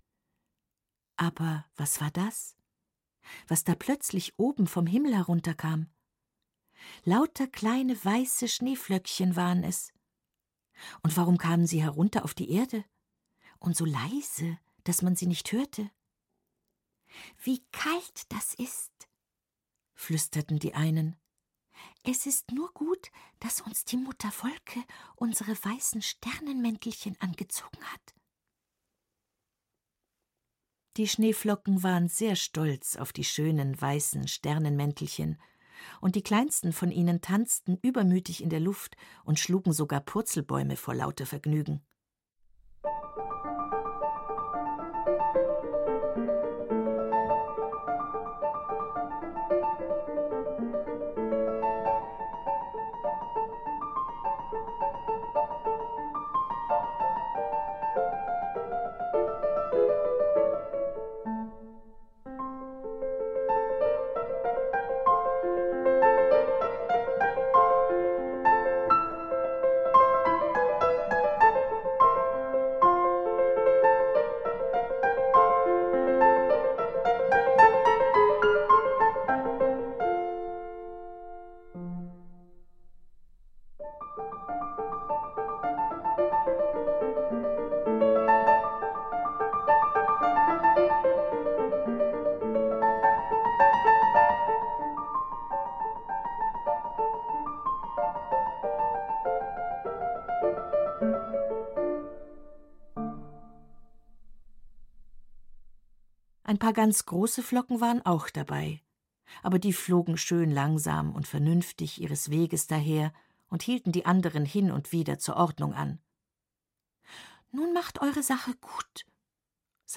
Celesta und Klavier
ein Märchen